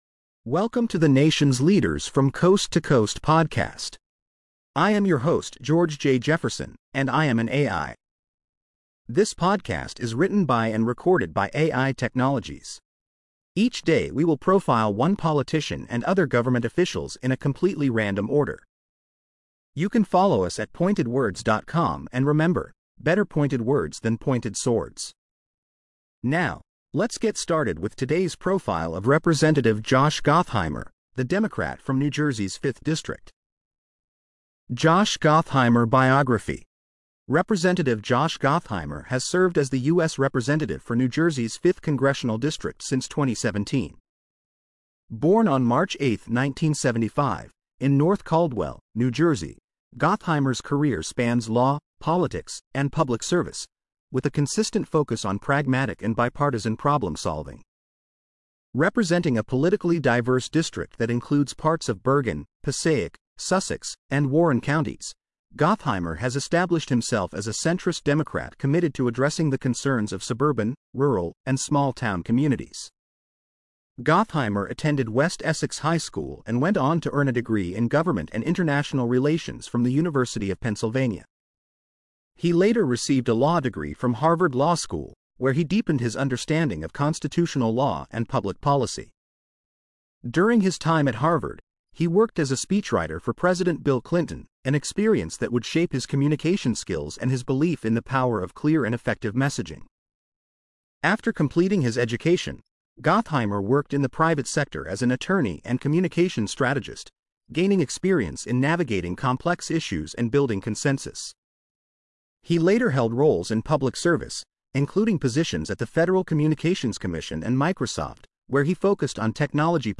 AI Profile of Representative Josh Gottheimer.